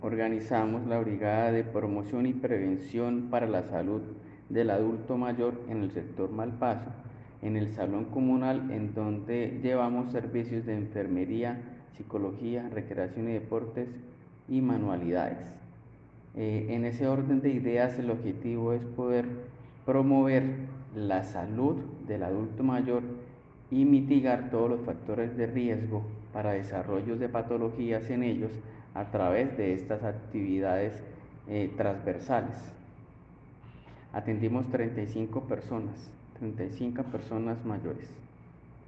Cristian Caballero, Secretario del Adulto Mayor.mp3